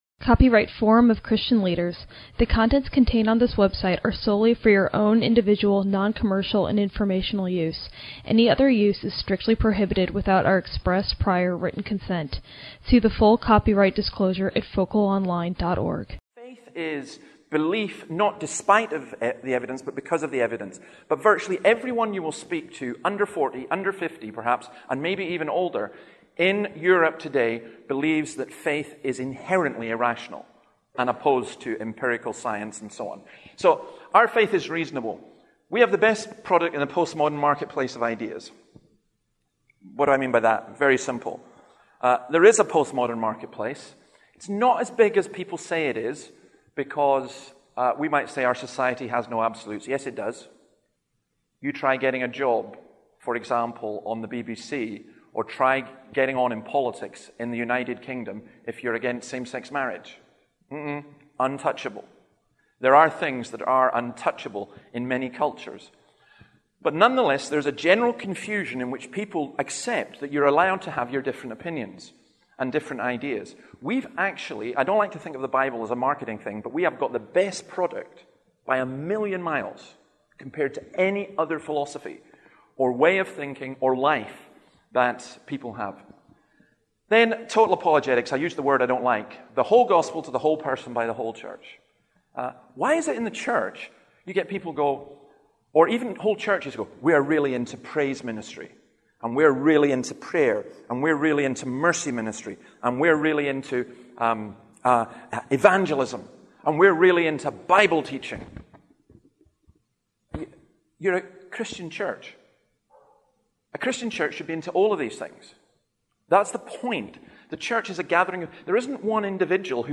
In this talk we look at how we can develop a church based strategy for persuasive evangelism.
Event: ELF Evangelists Network